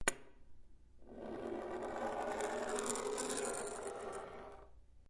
在桌子上拖动一个扳手
描述：将扳手拖过桌子
标签： 金属 木材 移动
声道立体声